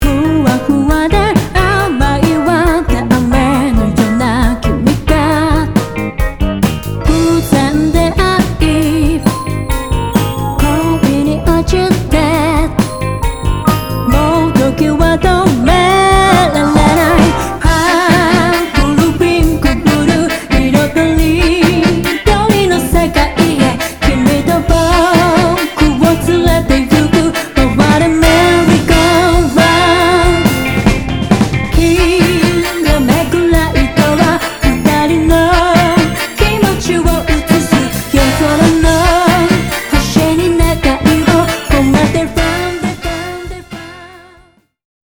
とびきりポップな
ジャンル(スタイル) JAPANESE POP / JAPANESE SOUL